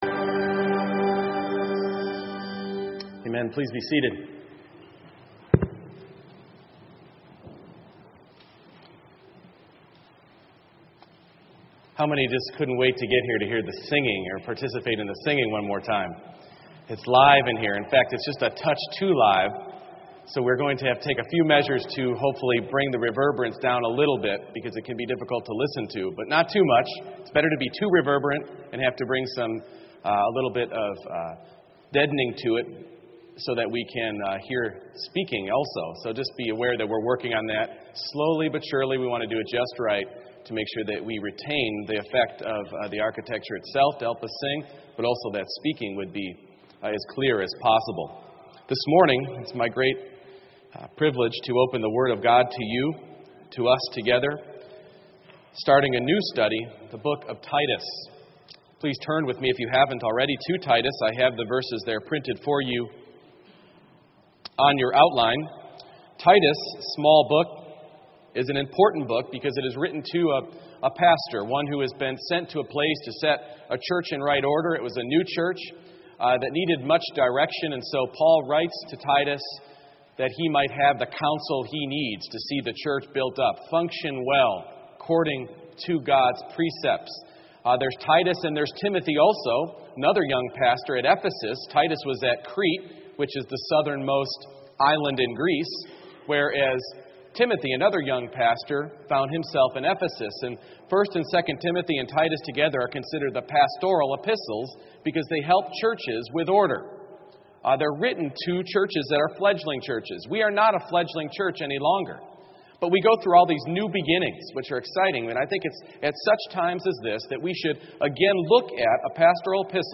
Passage: Titus 1:1-4 Service Type: Morning Worship